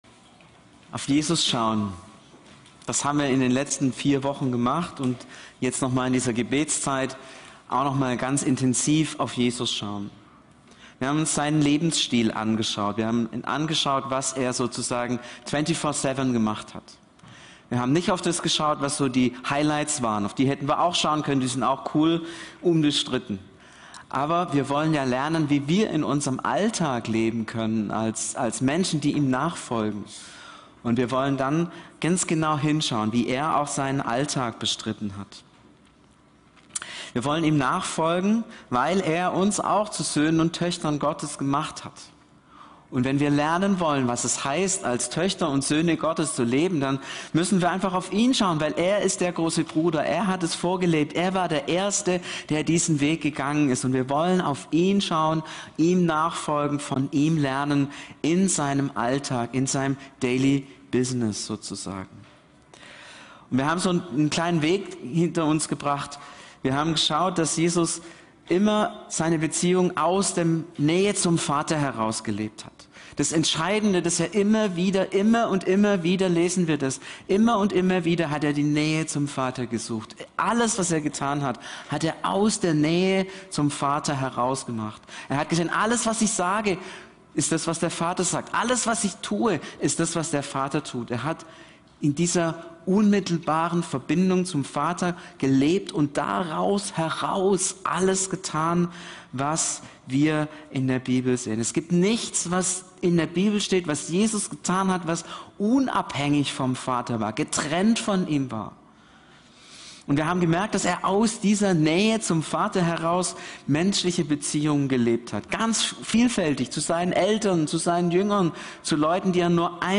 Predigten November 2025